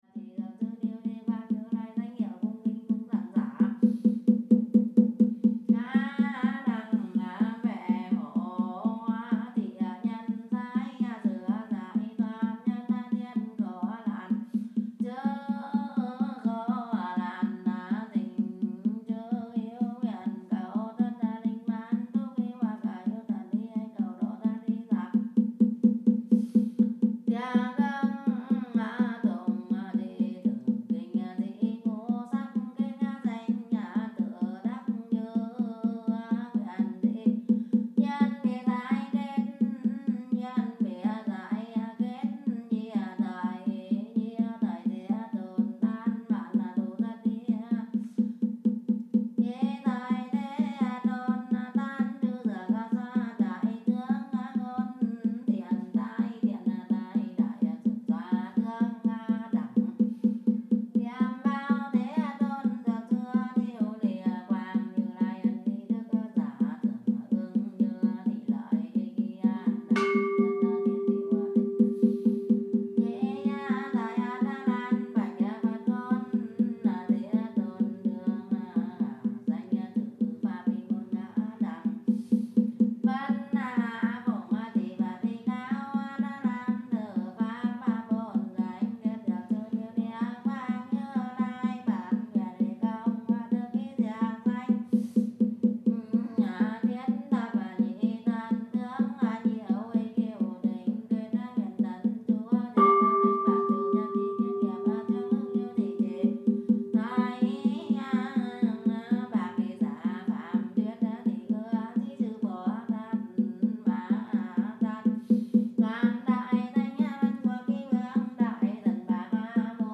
I recorded a lot of sounds in Vietnam, and here are some of those harder to categorize:
- Female chanter at temple at nighttime, near Bac Ninh (5:01)
chant.mp3